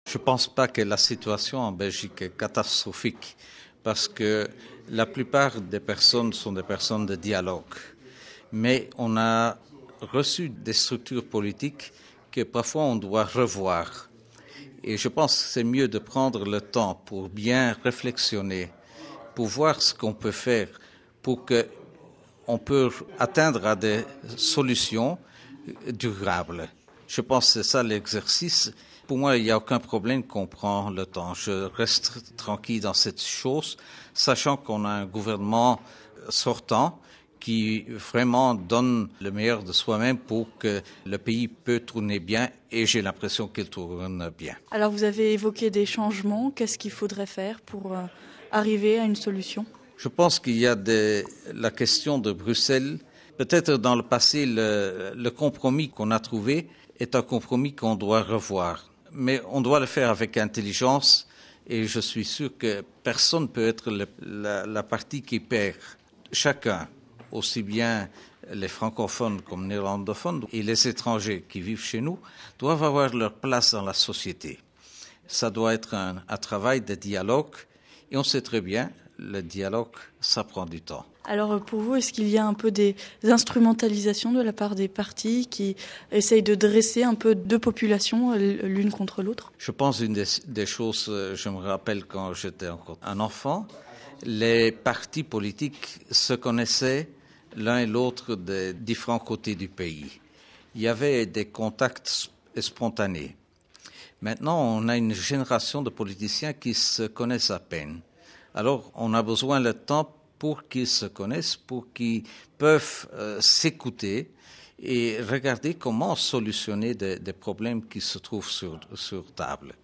Des propos recueillis